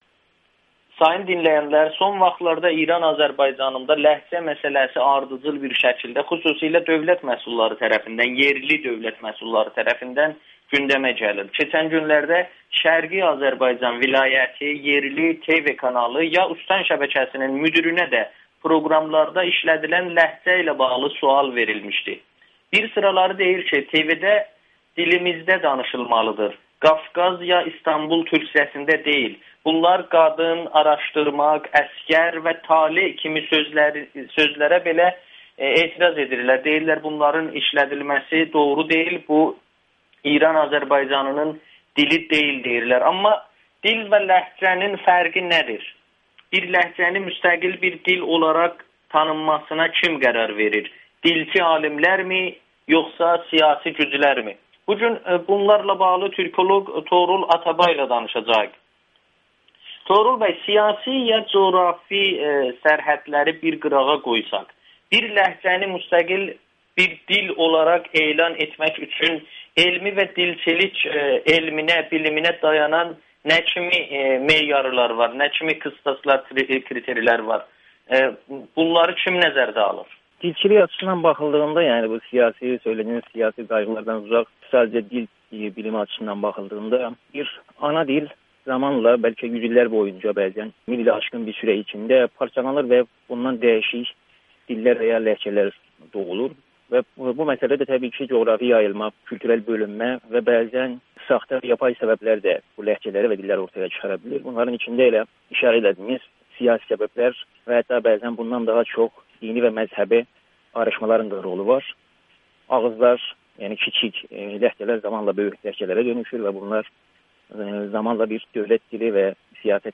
Güneyli türkoloq dilin siyasiləşməsi haqda danışır